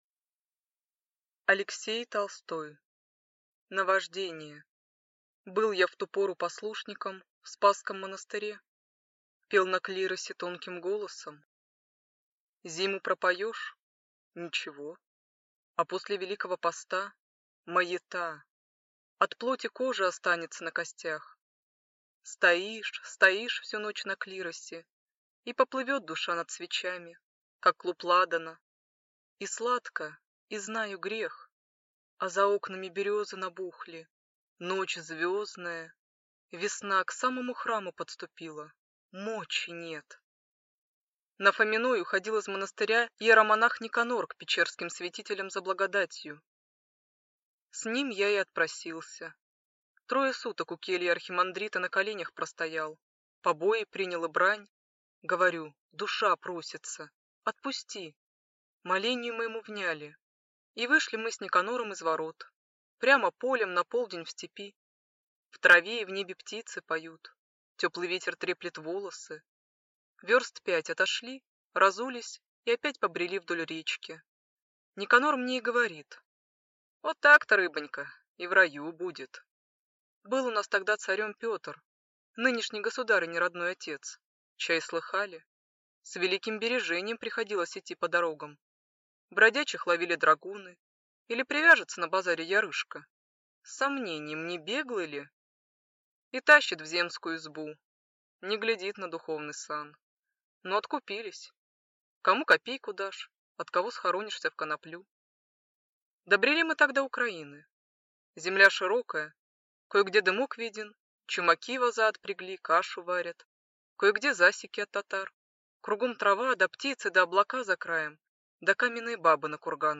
Аудиокнига Наваждение | Библиотека аудиокниг